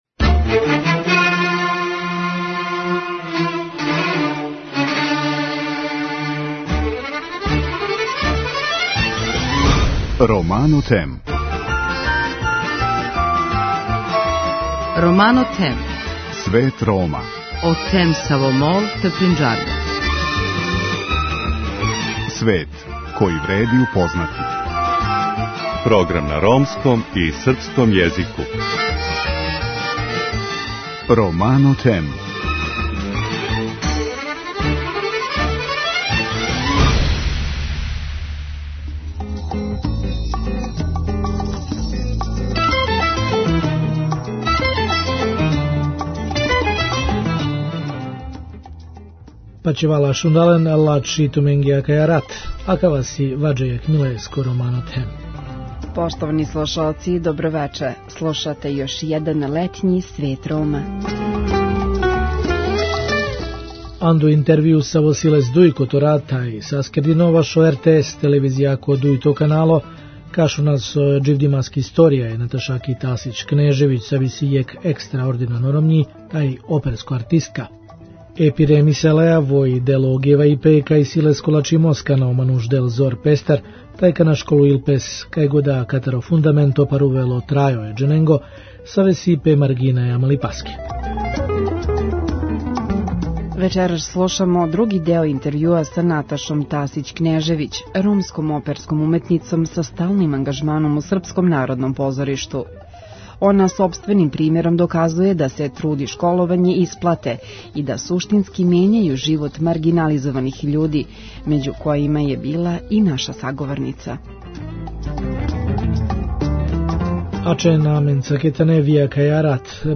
наставак интервјуа